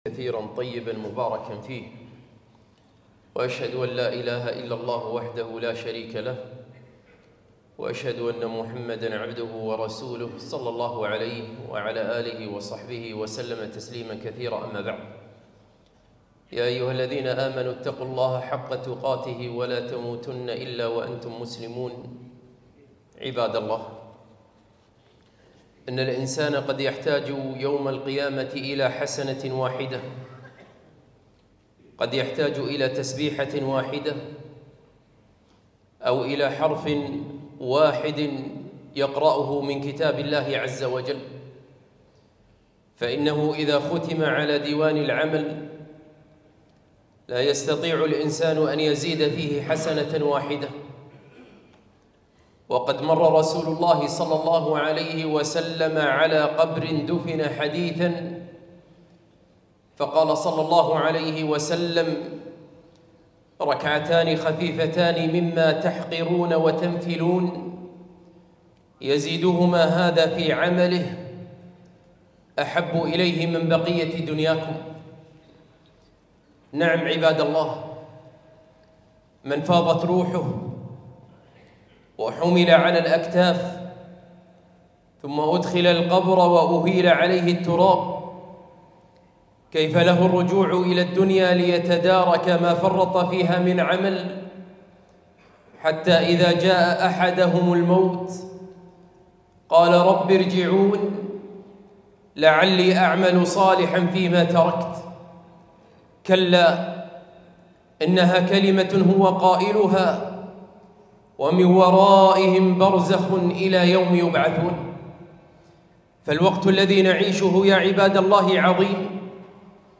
خطبة - الاجتهاد بالأعمال الصالحة في رمضان